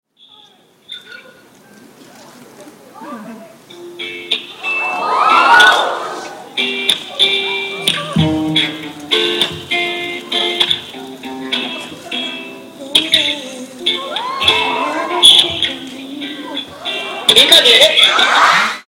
但是有現場連線 !!!!!!!!!!!!!!!!!!!!!! (完全瘋掉)
自彈自唱聲音好迷人喔  只唱兩句真的太少了